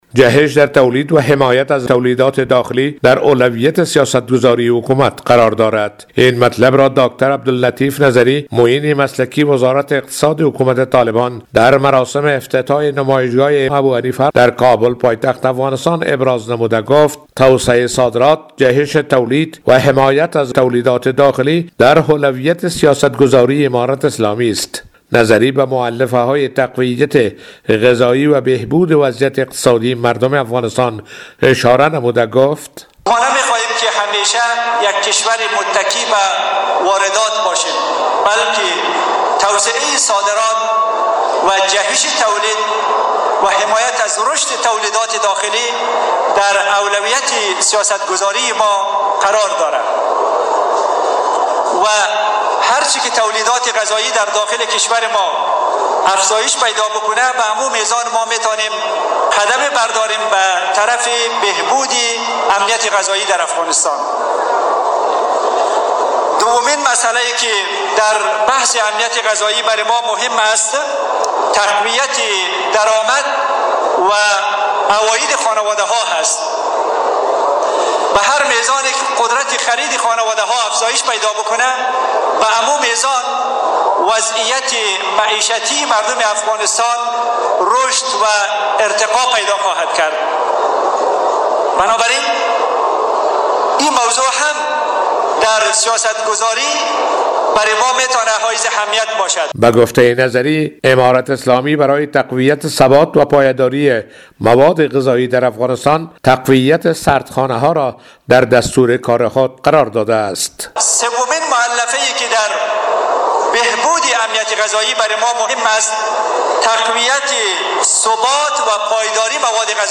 در مراسم افتتاح نمایشگاه ملی و بین المللی صنعت و بازرگانی در کابل